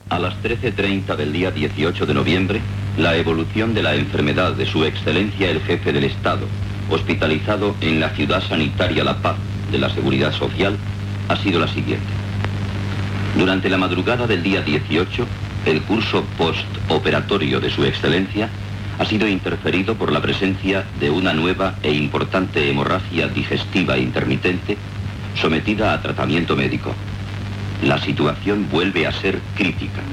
Informatiu
Extret de Crònica Sentimental de Ràdio Barcelona emesa el dia 5 de novembre de 1994.